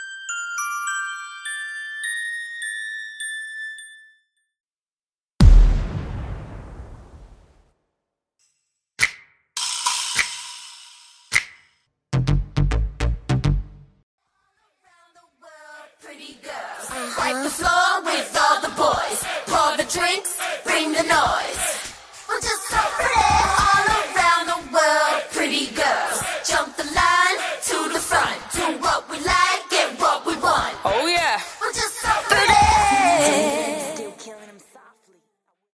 Studio Bassline Synth Stem
Studio Boom Effect Stem
Studio Drums Stem
Studio Organ Stem
Studio Percussion Stem
Studio Synth Keys Stem
Studio Synth Stem